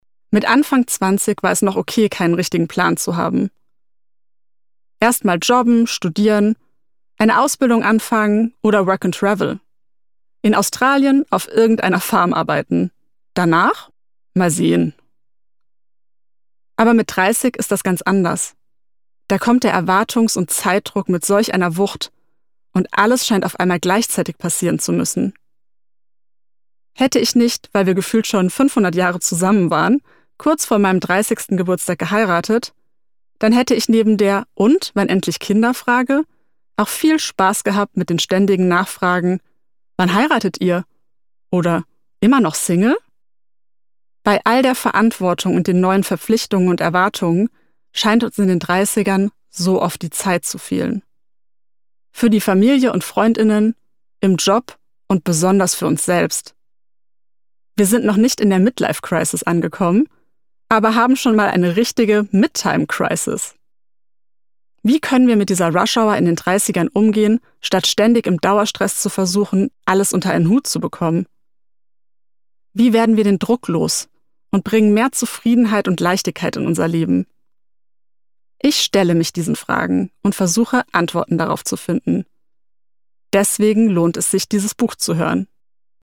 Format: Download-Hörbuch  (MP3)
Fassung: Ungekürzte Ausgabe